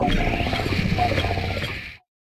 Cri de Mite-de-Fer dans Pokémon Écarlate et Violet.